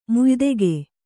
♪ muydege